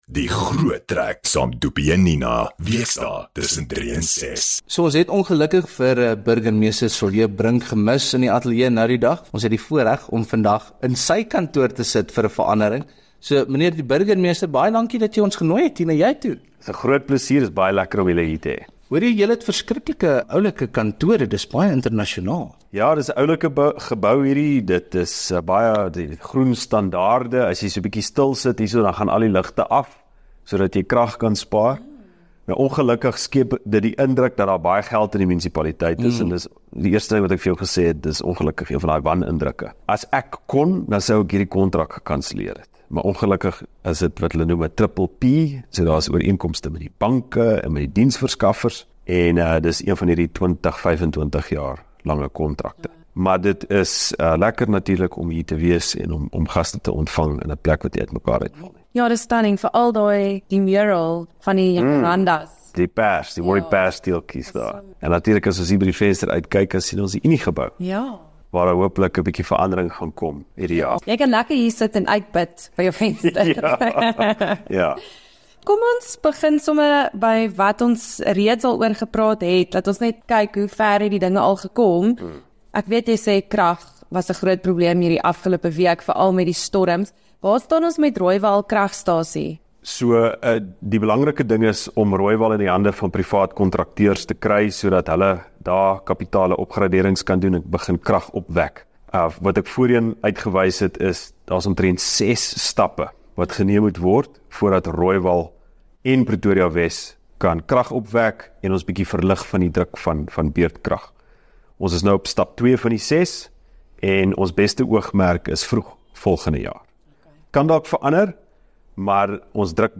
Ons het ons Februarie gesprek met die Burgemeester gemis omdat hy besig was maar hy het vir ons na sy kantoor toe genooi en daar het ons gepraat oor al die nuwe verwikkellinge in die stad en ook waar en hoe die publiek verder kan uithelp om seker te maak Pretoria bly SA se mooiste stad.